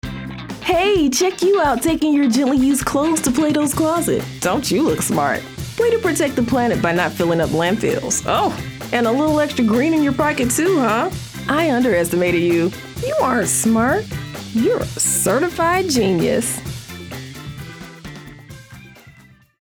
Female
Yng Adult (18-29), Adult (30-50)
My voice is warm, confident, friendly, and versatile, adapting to the needs of each project.
Television Spots
Words that describe my voice are Friendly, Relatable, Casual.